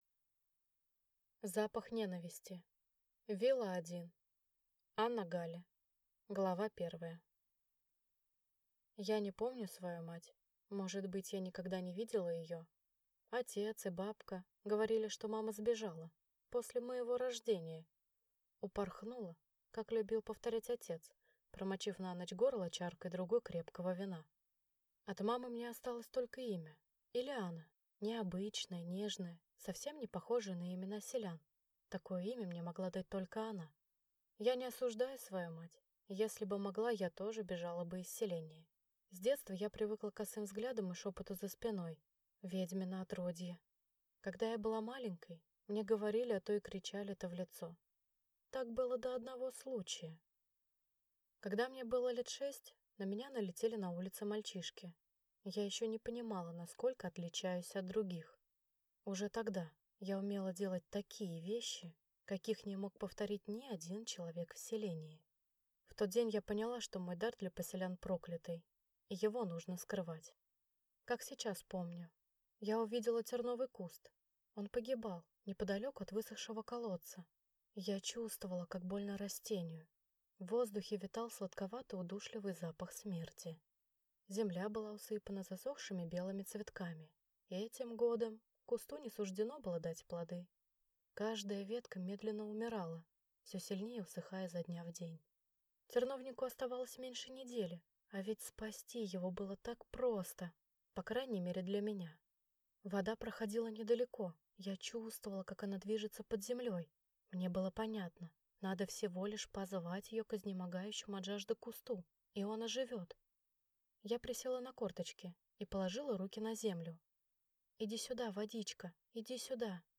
Аудиокнига Вила-1. Запах ненависти | Библиотека аудиокниг